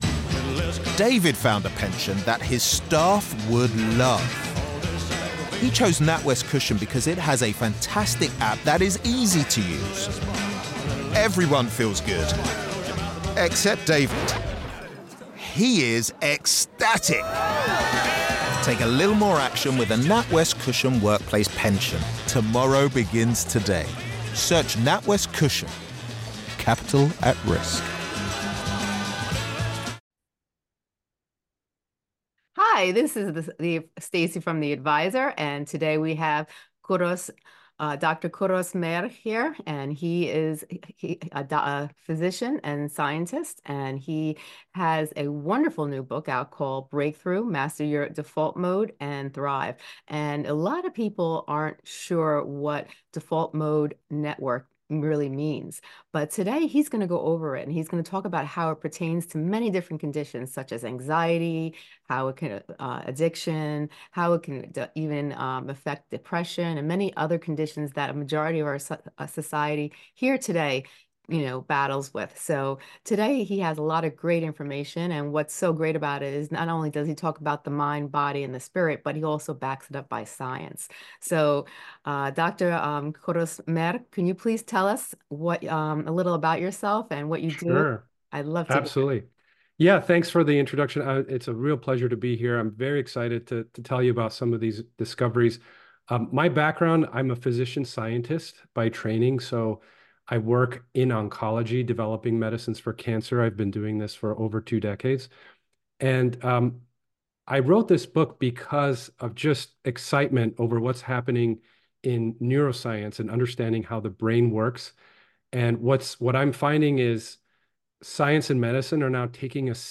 hosts a thought-provoking conversation